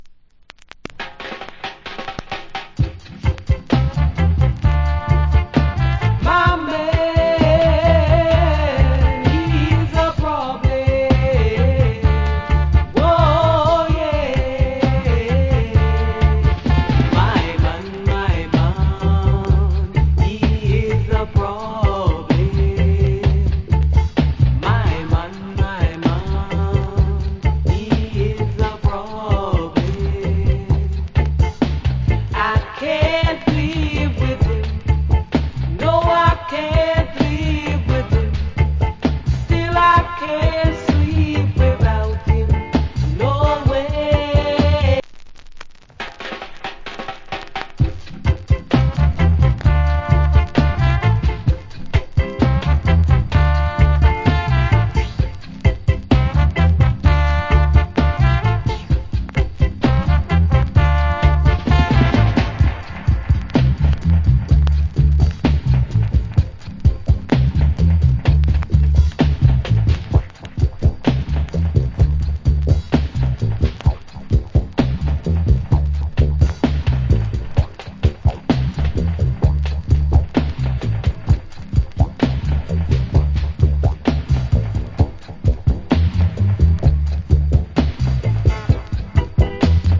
Early 80's Nice Female Reggae Vocal.